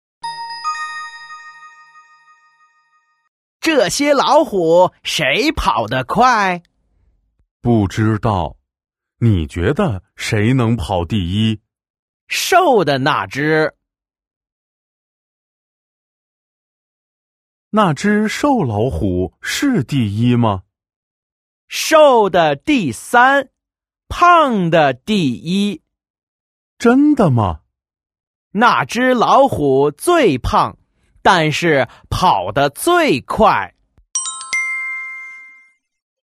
Đoạn hội thoại 1:
Đoạn hội thoại 2: